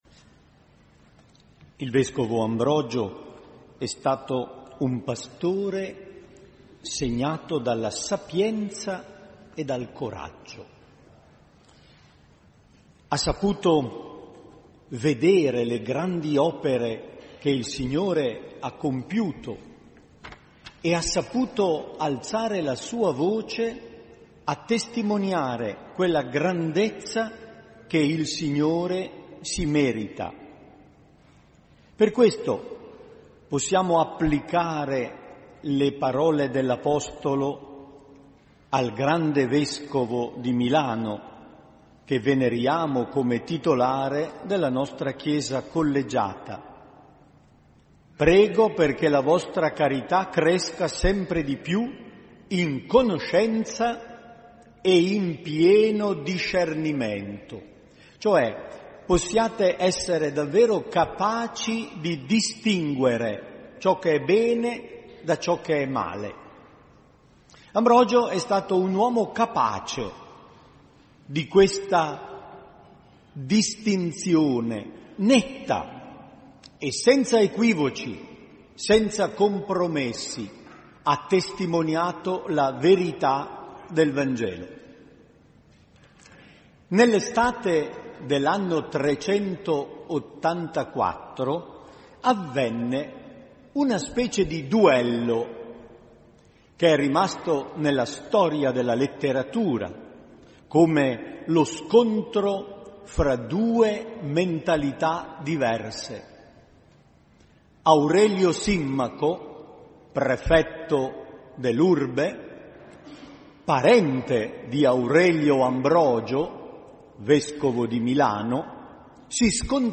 Omelia per Sant’Ambrogio